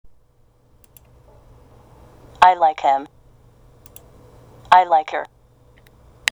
hは “hit” (ヒット) や “help” (ヘルプ) のような単語では、後に続く母音によって「ハヒフヘホ」と聞こえますが、ネイティブが言う“I like him/her.” (私は彼が/彼女が好きです。)などで使われる代名詞のhの発音は非常に弱く、聞き取れないことがあります。